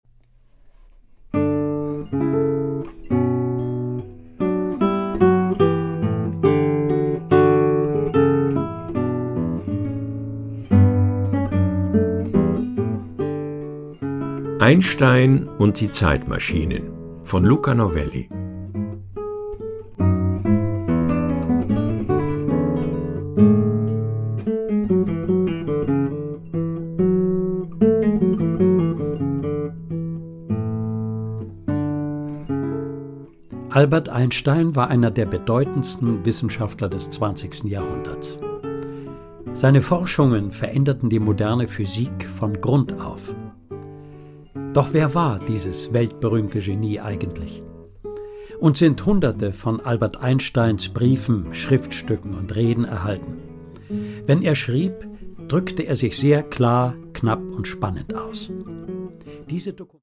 Produkttyp: Hörbuch-Download
Fassung: ungekürzte Fassung